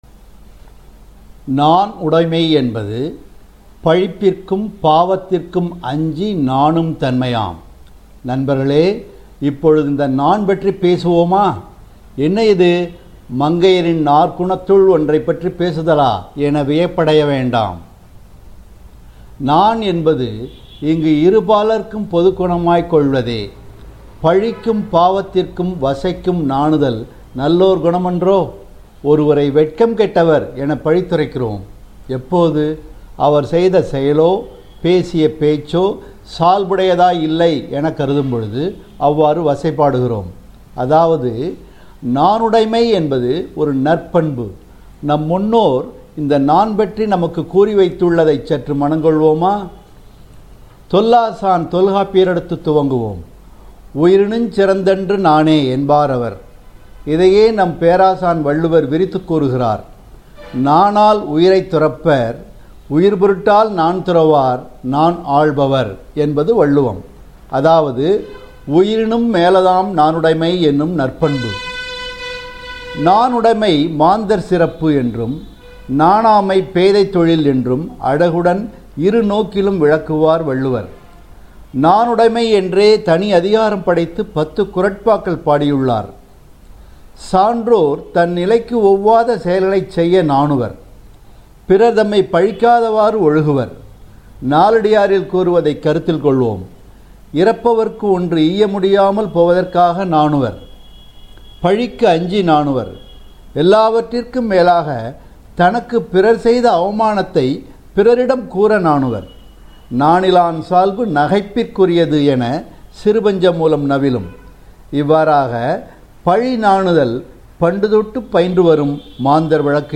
கேளுங்கள் என் சிற்றுரையை ; கூறுங்கள் உங்கள் கருத்தை!